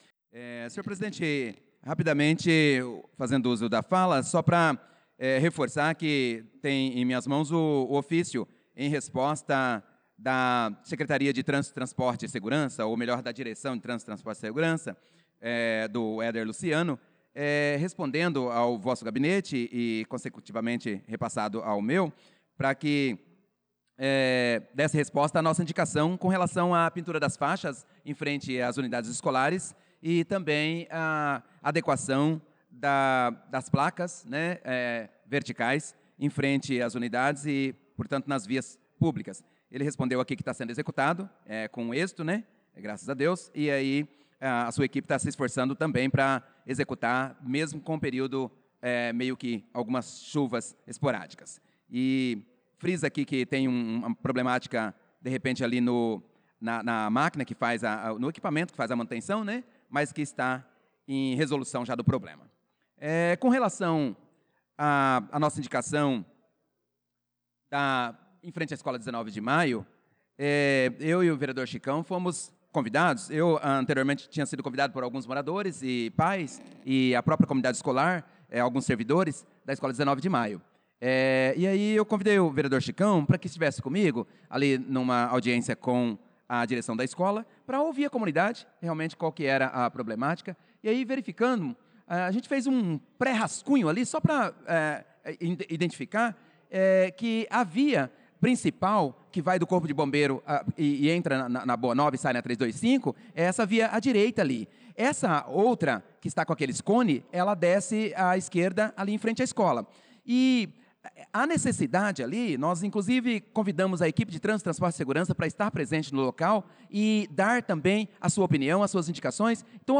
Pronunciamento do vereador Prof. Nilson na Sessão Ordinária do dia 18/3/2025